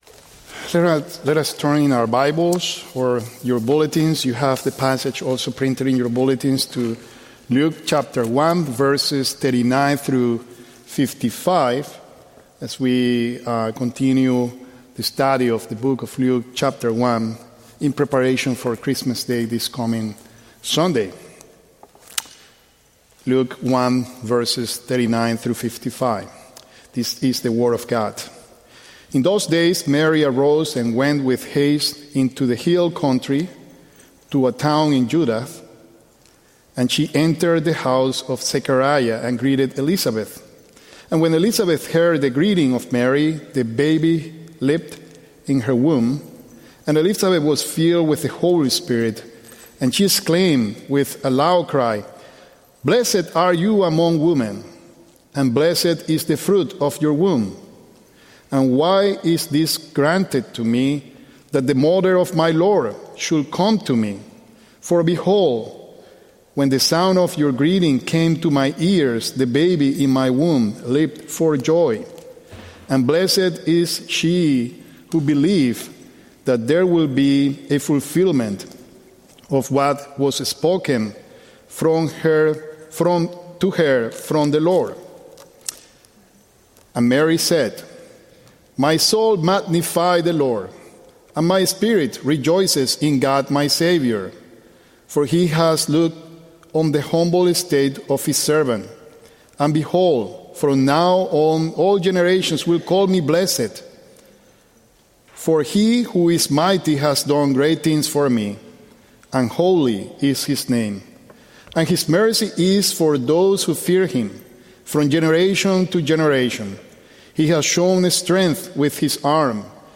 The Lord Has Done It | SermonAudio Broadcaster is Live View the Live Stream Share this sermon Disabled by adblocker Copy URL Copied!